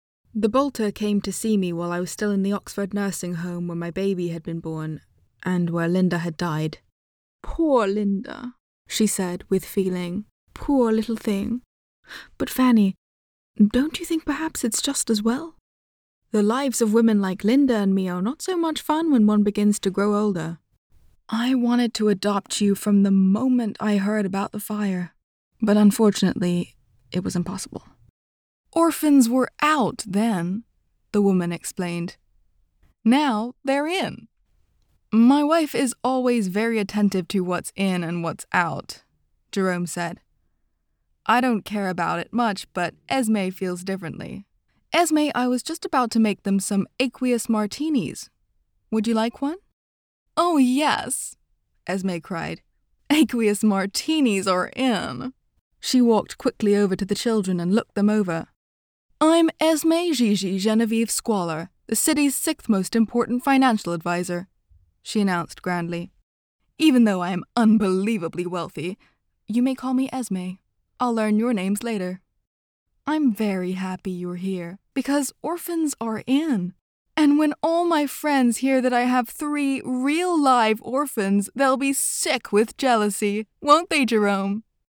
Audiobook Reel
• Home Studio
Soft and melodic
A multi-national, her voice bends effortlessly from one accent to the next, all utterly believable and with a characterful bite.